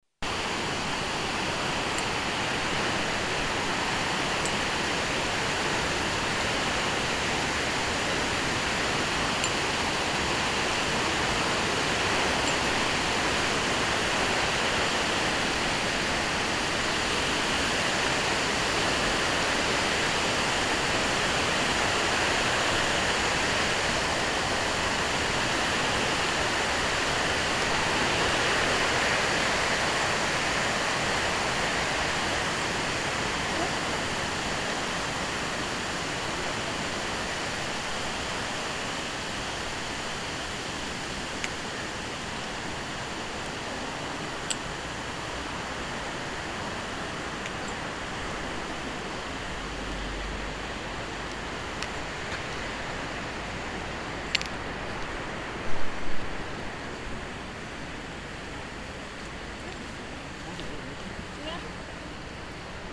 Der Wasserwanderweg in Hittisau entlang der Bolgenach